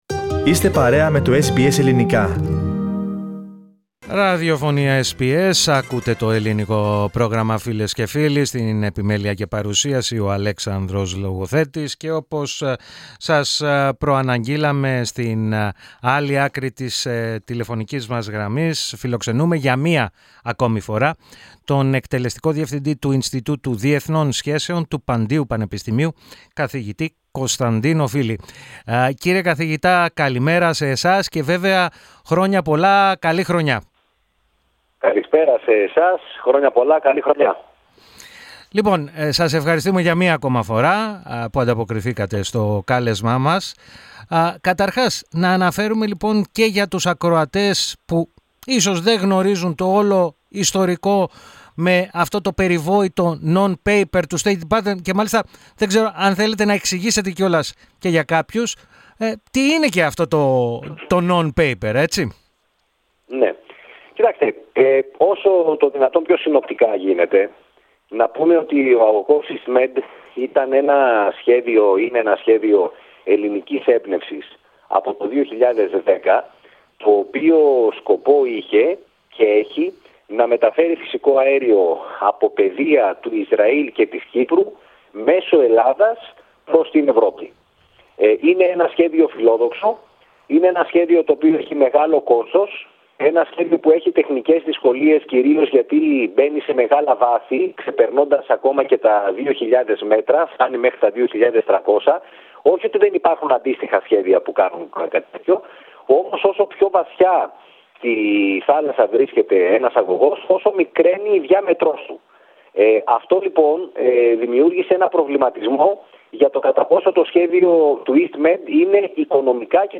Επιπλέον ερωτήθηκε για τις παρούσες πολιτικές και οικονομικές εξελίξεις στην Τουρκία. Ακούστε ολόκληρη τη συνέντευξη, πατώντας το σύμβολο στο μέσο της κεντρικής φωτογραφίας.